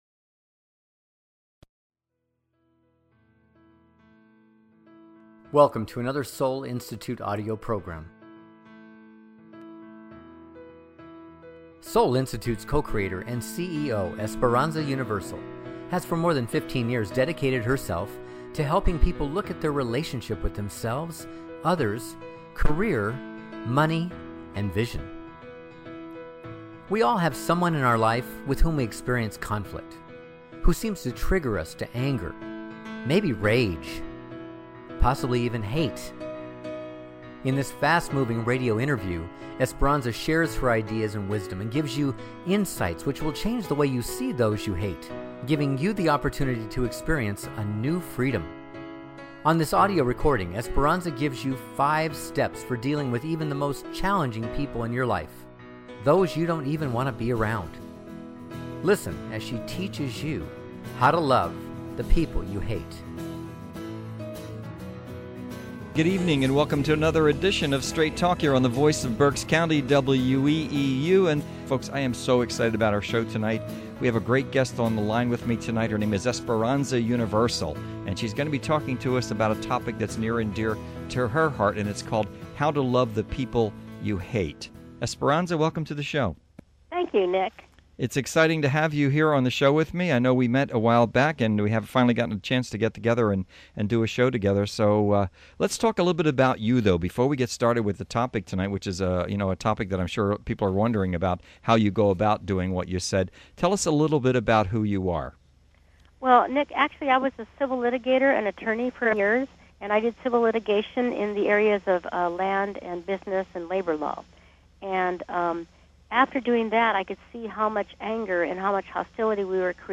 "How to Love the People You Hate" Radio Interview